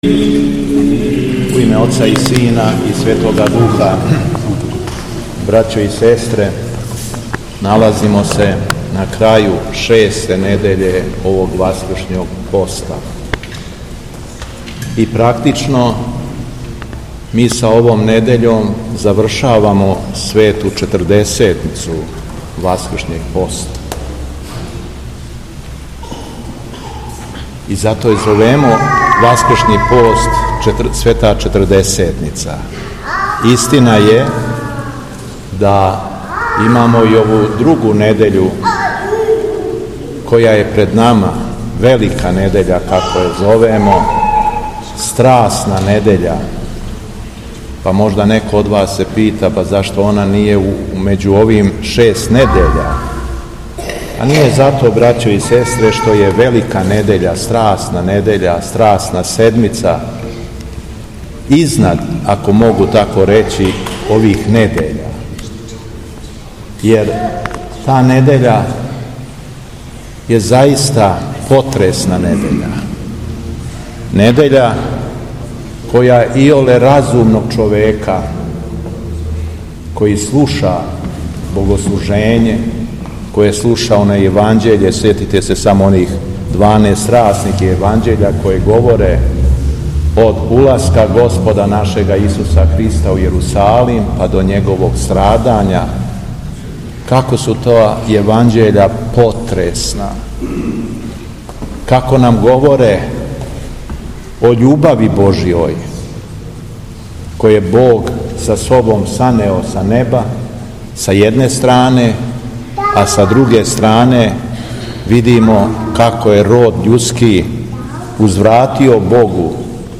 Дана 11. aприла 2025. године, у петак Цветне недеље Великог поста, Његово Високопреосвештенство Митрополит шумадијски Господин Јован служио је Литургију пређеосвећених Дарова у храму Успења Пресвете Богородице у Младеновцу.
Беседа Његовог Високопреосвештенства Митрополита шумадијског г. Јована